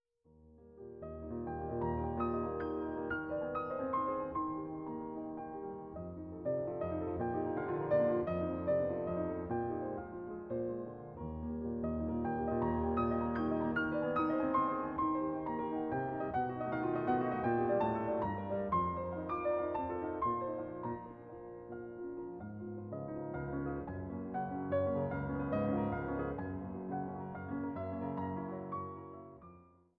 Nr. 18 in f-Moll Molto allegro